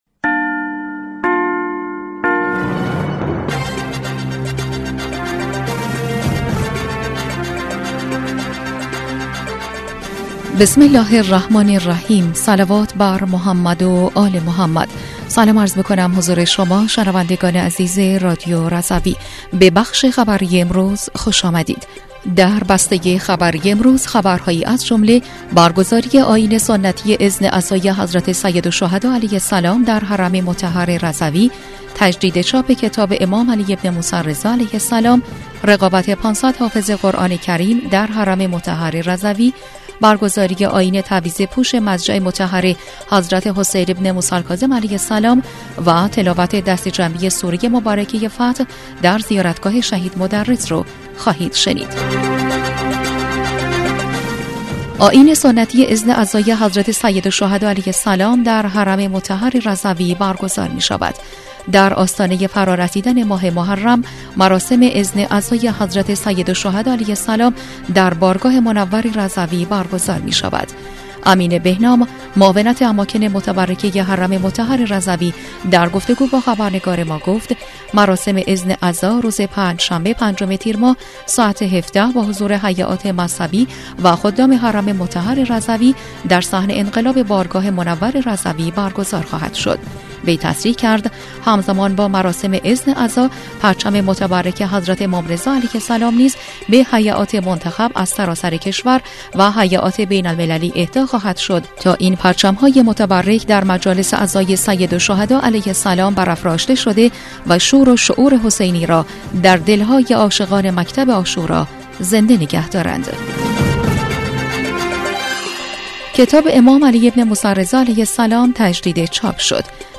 بسته خبری ۳ تیرماه ۱۴۰۴ رادیو رضوی/